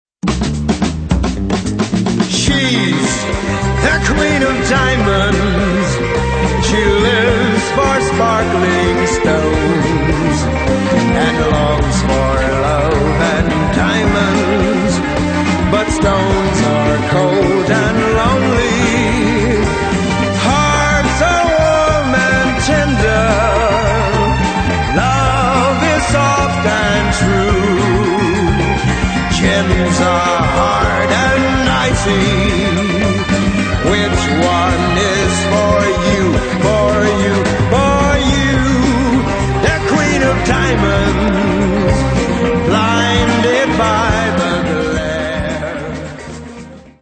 22 groovy tv & movie themes from 1969-1984